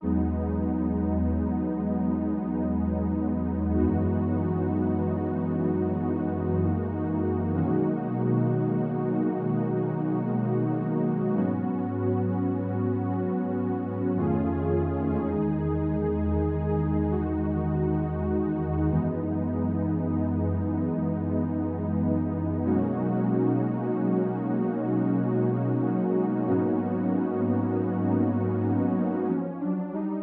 14 pad B2.wav